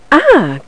femaleh1.mp3